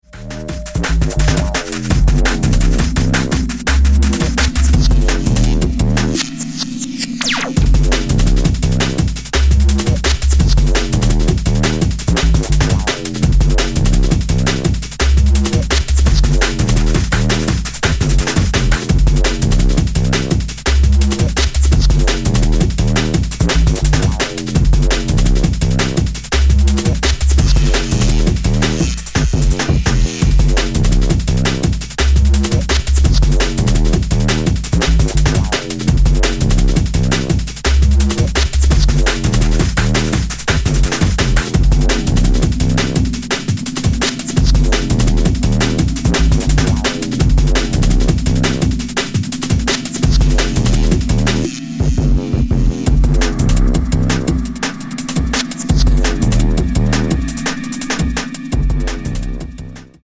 ＊視聴音源は実物のレコードから録音してます。